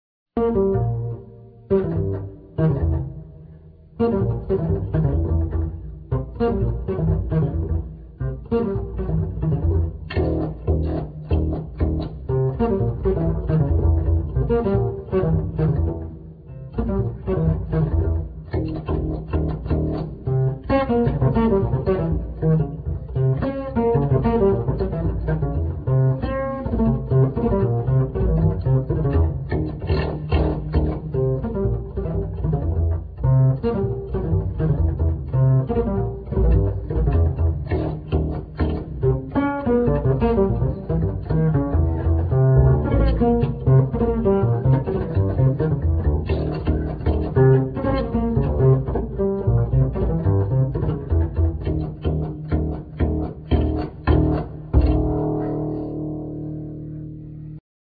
Double bass,Piano